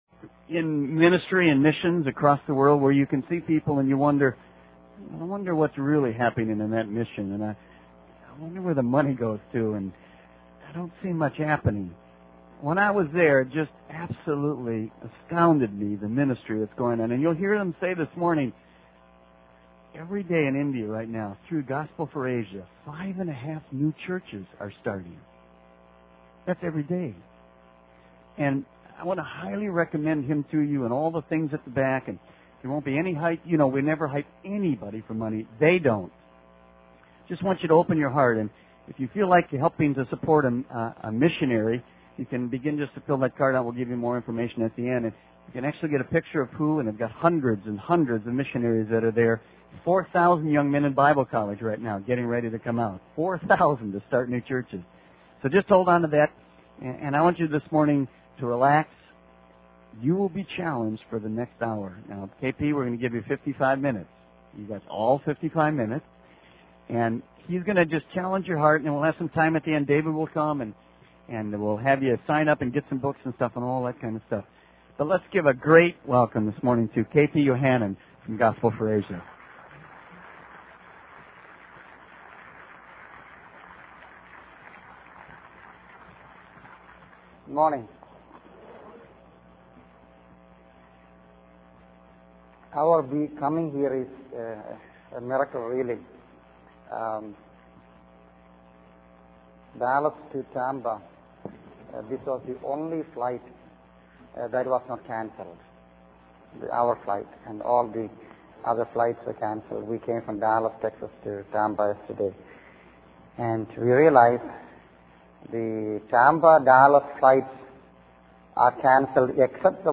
In this sermon, the speaker reflects on a powerful encounter he had while reading a newspaper in Bombay.